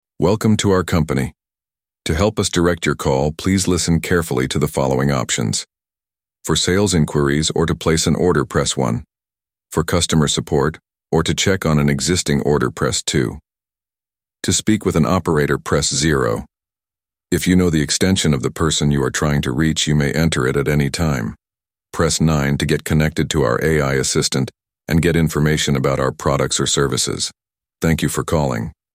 The Most Realistic Speech
ElevenLabs' advanced voice generator delivers incredibly realistic, human-like speech in 32 languages. Elevate your IVR systems and personalized voice messages with natural-sounding voices that captivate and engage your audience.